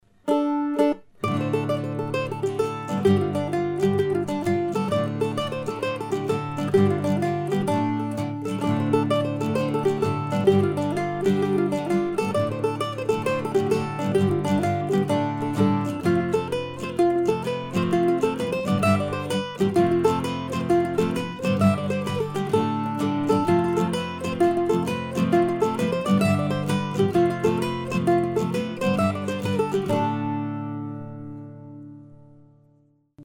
Jigs, pt. 1
We usually play it first as a jig and then as a reel and, if we have time, we'll sometimes go back and forth playing the A section in 4/4 and the B section in 6/8. The title, of course, refers to snowfall in northeast Iowa.